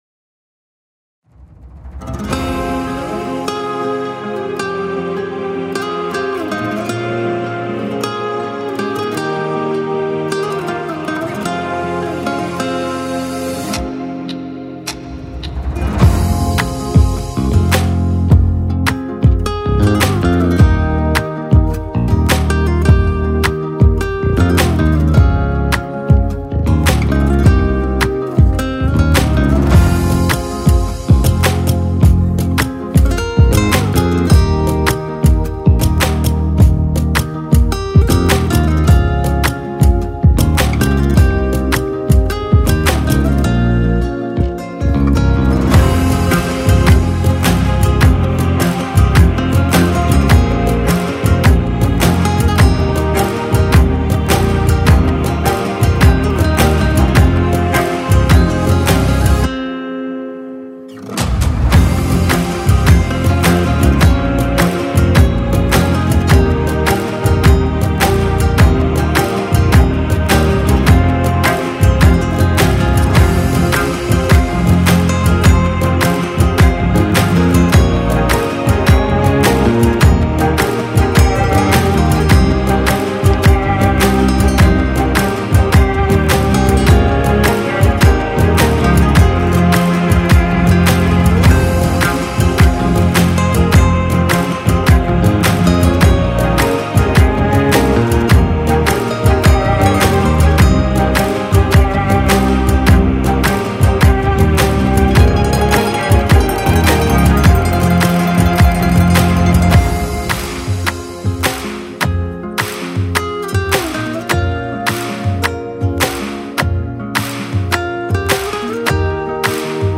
بیکلام